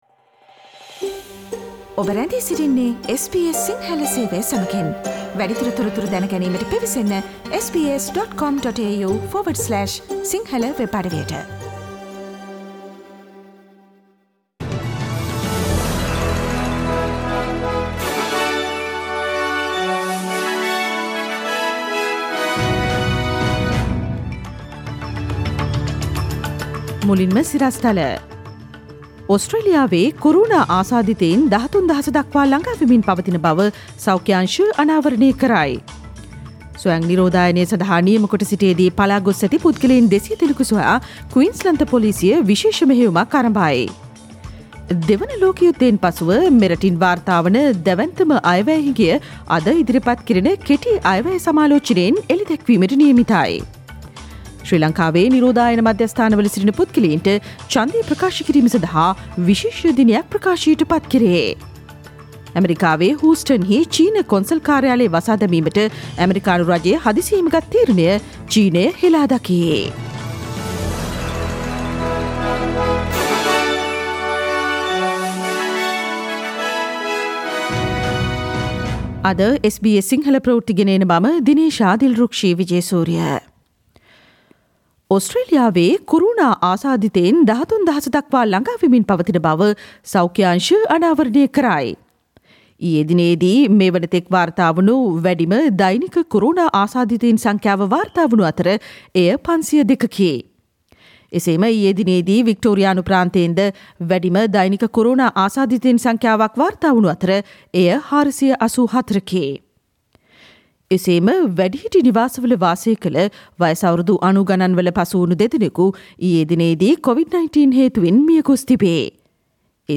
Daily News bulletin of SBS Sinhala Service: Thursday 23 July 2020
Today’s news bulletin of SBS Sinhala radio – Thursday 23 July 2020.